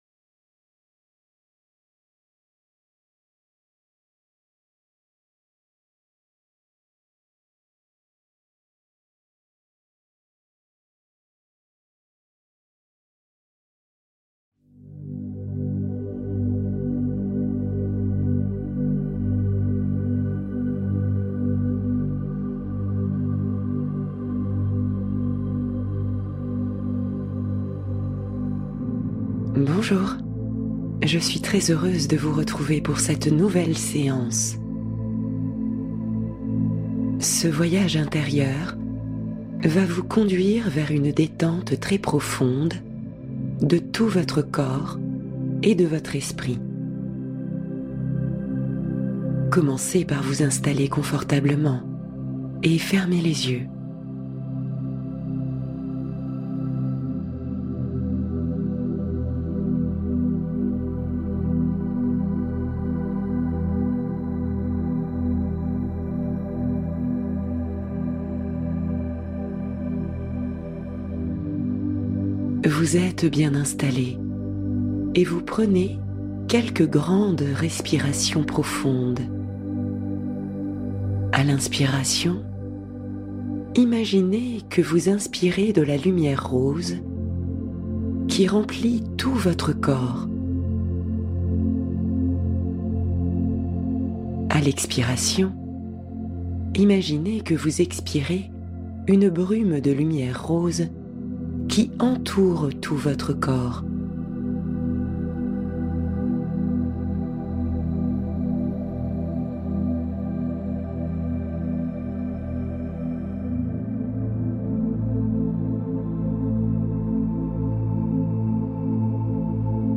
Éveil de Soi : Méditation profonde pour une transformation intérieure